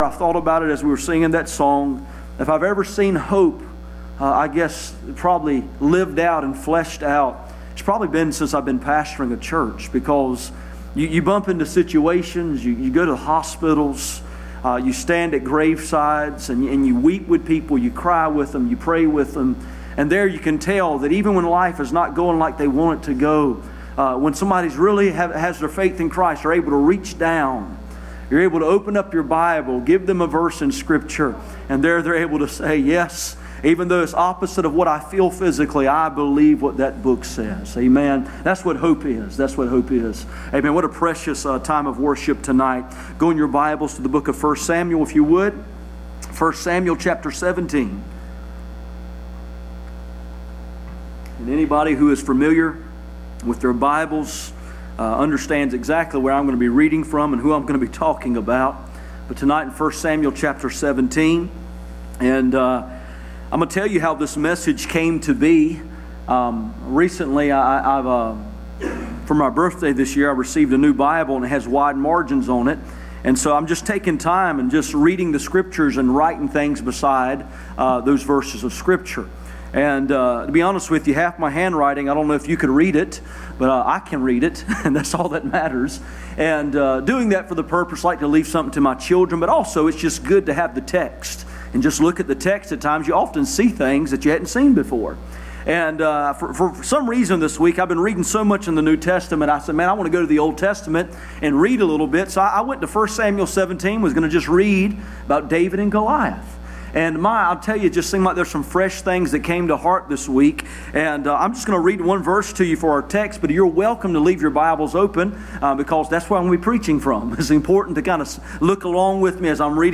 None Passage: 1 Samuel 17:50-51 Service Type: Sunday Evening %todo_render% « Leaping over walls Wake up